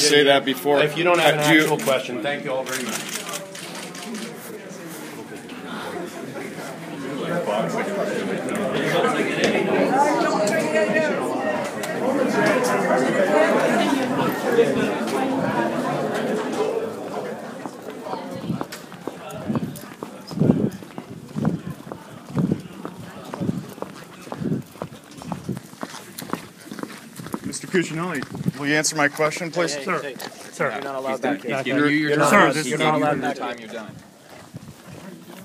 Here’s the audio of C&BP trying to get an answer before being stopped by state troopers (fast forward to :27 for the question and interaction with state troopers):